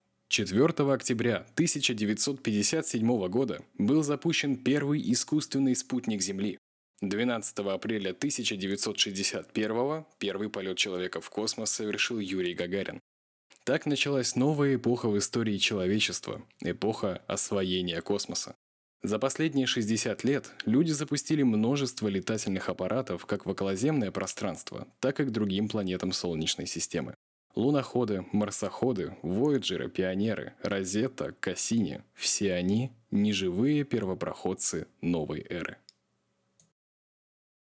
AKG P120 Behringer U-Phoria Umc22